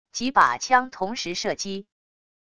几把枪同时射击wav音频